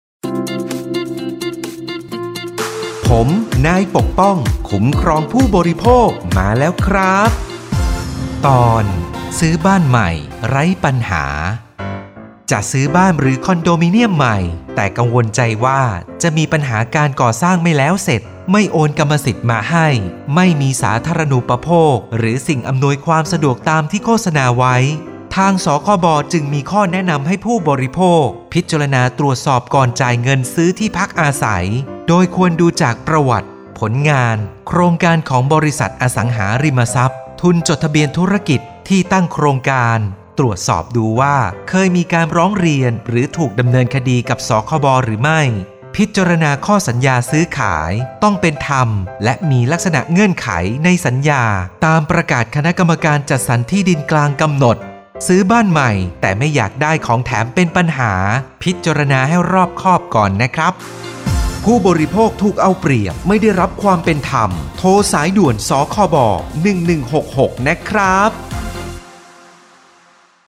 สื่อประชาสัมพันธ์ MP3สปอตวิทยุ ภาคกลาง
017.สปอตวิทยุ สคบ._ภาคกลาง_เรื่องที่ 17_.mp3